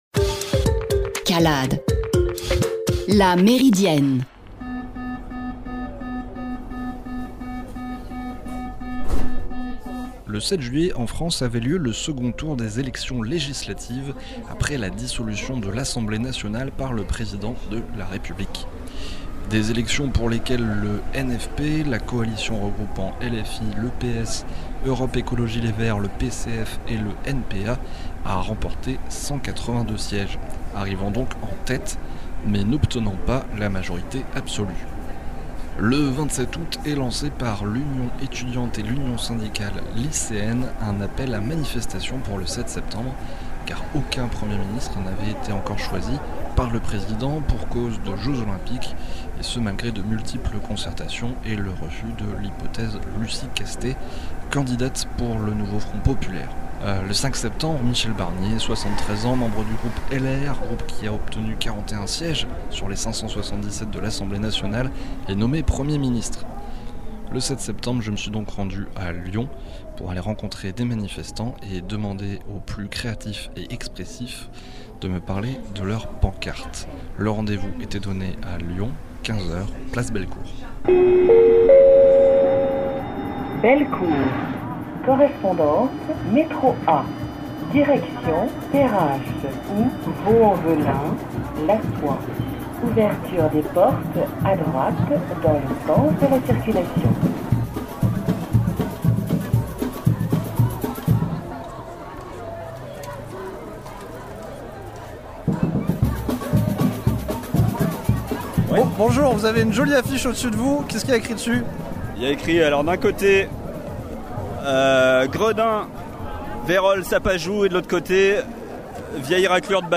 calade_Meridienne Chronique – 130924 Reportage Manif